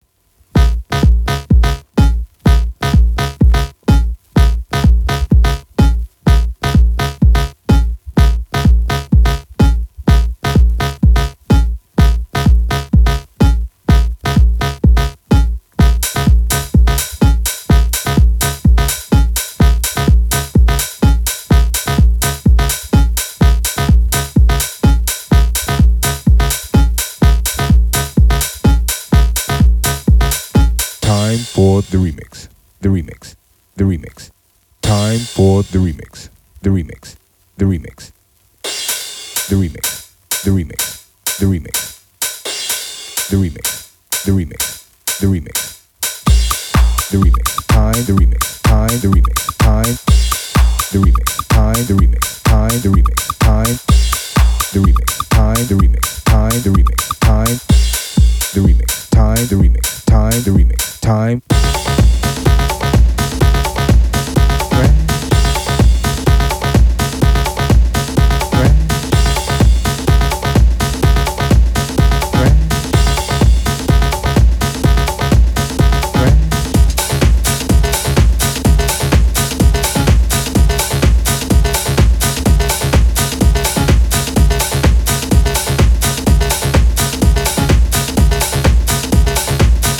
Chicago House